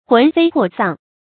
魂飞魄丧 hún fēi pò sàng
魂飞魄丧发音